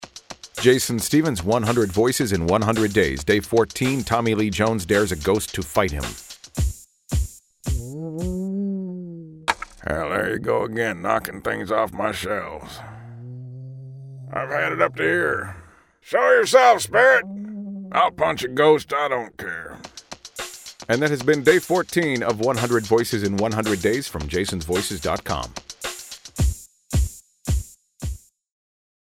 So, I jumped in the recording booth, dug into a couple of clips from his films, and hit record.
Tags: celebrity sound alike, Tommy Lee Jones impression